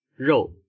口音（男声）